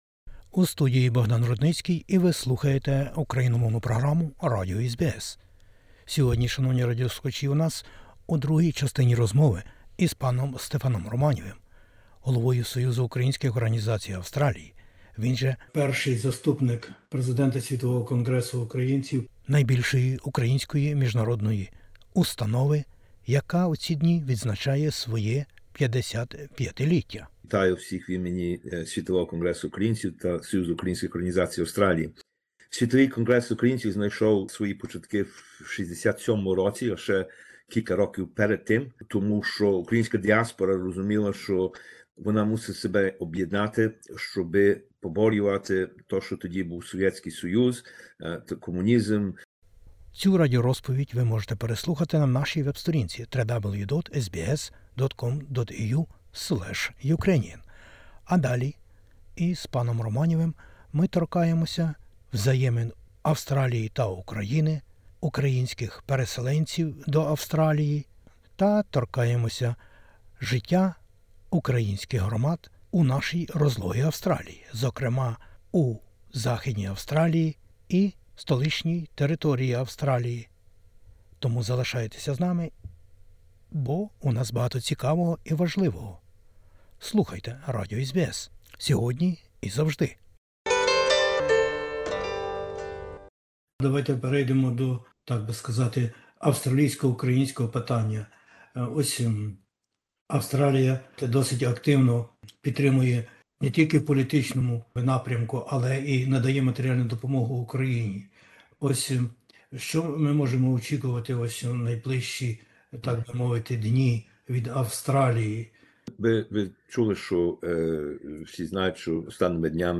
У розмові